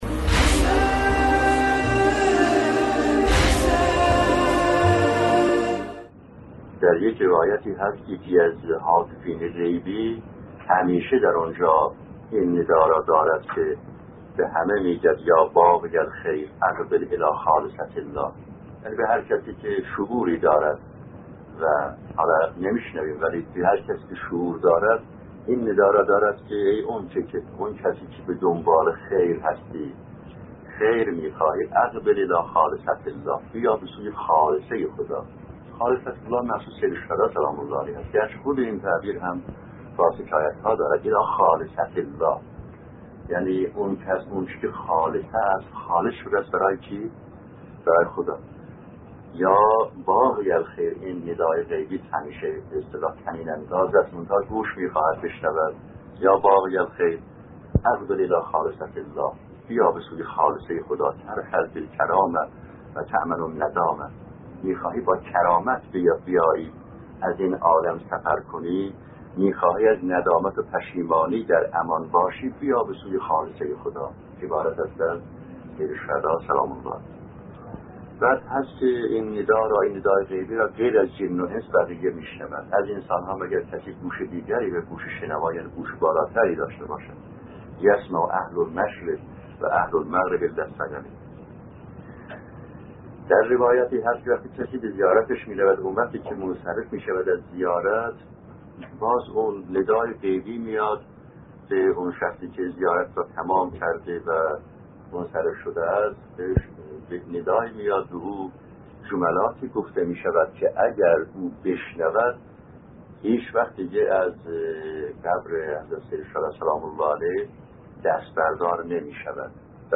در ادامه، قسمت سی‌و‌نهم این سلسله‌گفتار را با عنوان «خیر دنیا در انس با سیدالشهدا(ع) است» می‌شنوید.